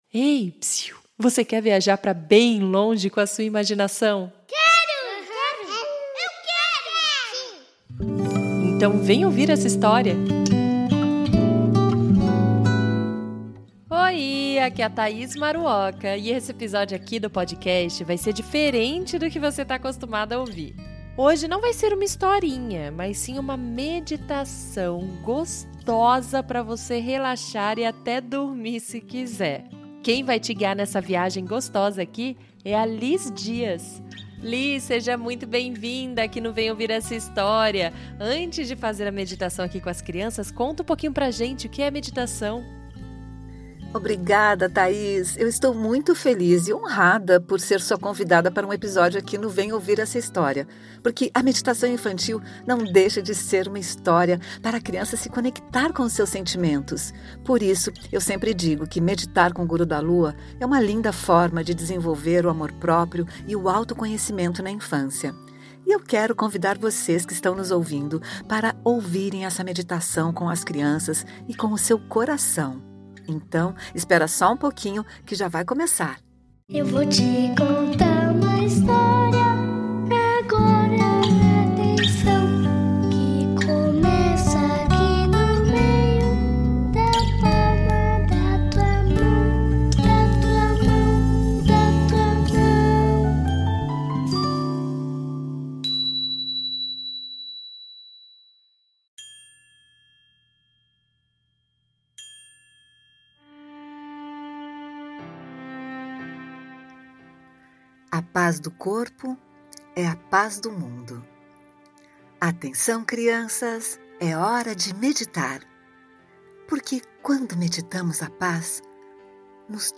Meditação: A Paz Do Corpo É A Paz Do Mundo